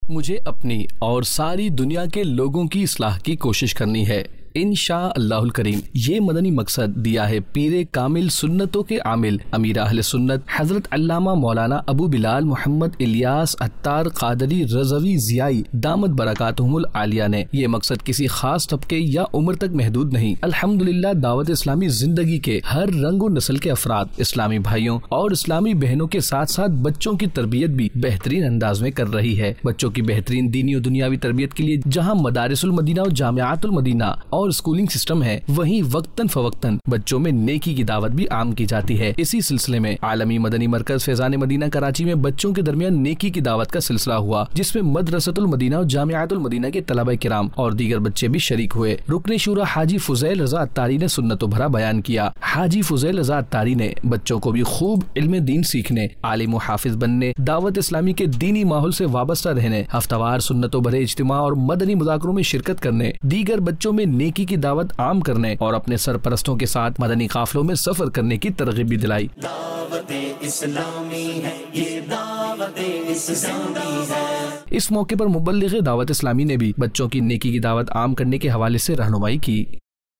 News Clips Urdu - 10 December 2023 - Hamara Madani Maqsad Mujhay Apni Aur Sari Duniya Kay logon Ki Islah Ki Koshish Karni Hai Dec 20, 2023 MP3 MP4 MP3 Share نیوز کلپس اردو - 10 دسمبر 2023 - ہمارا مدنی مقصد مجھے اپنی اور سارے دنیا کے لوگوں کی اصلاح کی کوشش کرنی ہے